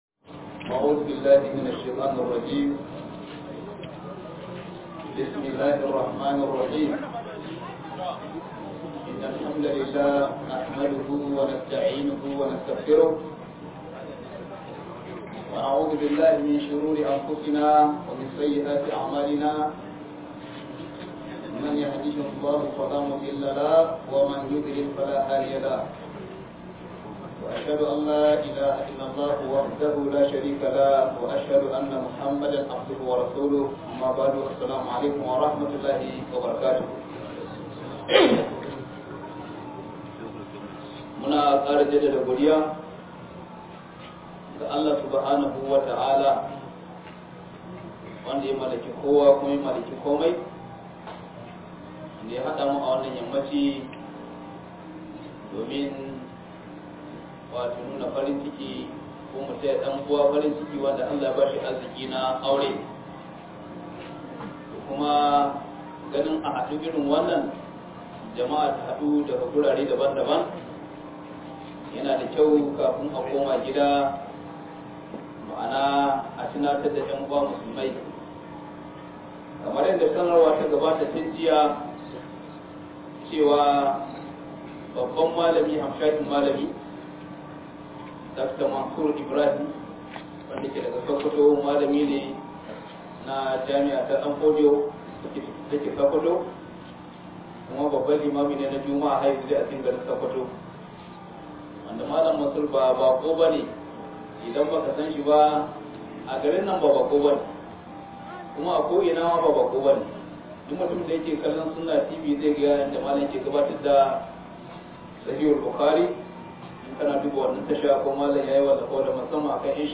RUHAMA'U BAINAHUM - MUHADARA